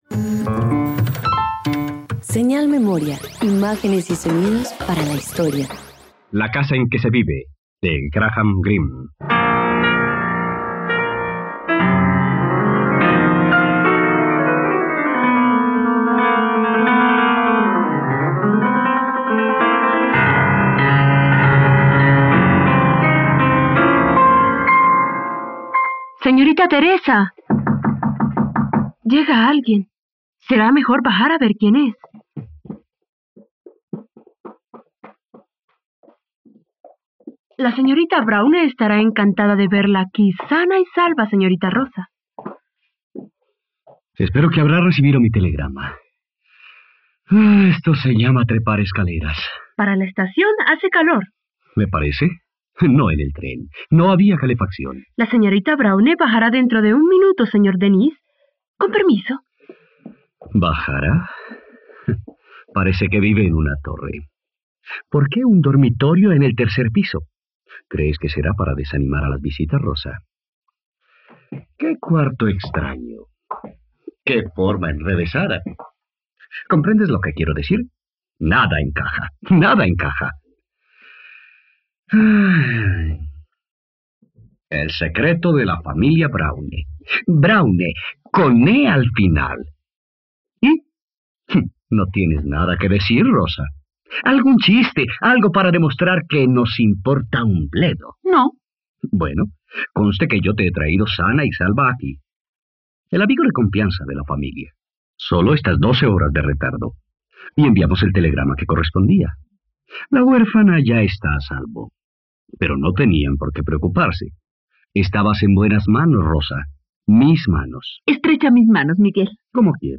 La casa en que se vive - Radioteatro dominical | RTVCPlay